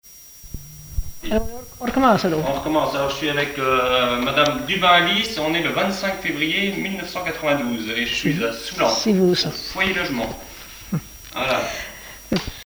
Enquête sur les chansons populaires
Pièce musicale inédite